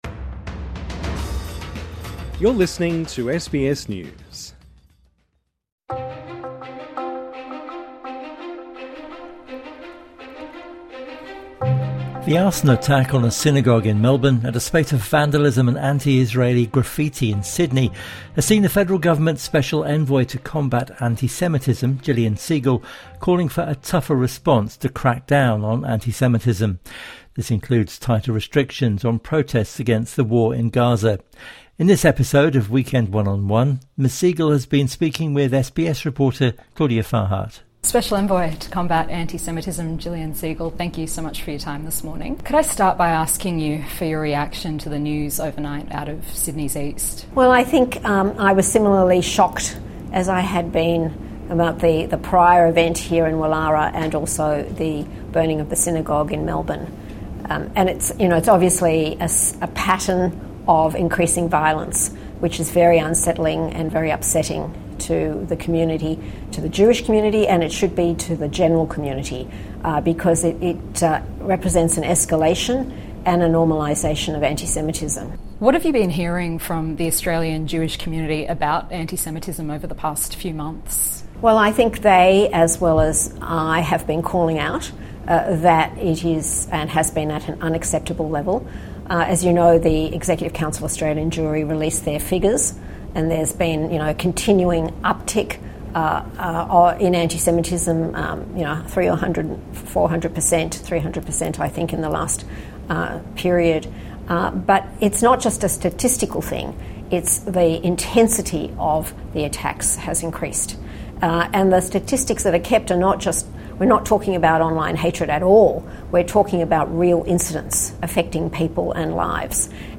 INTERVIEW: Anti-semitism envoy Jillian Segal speaks to SBS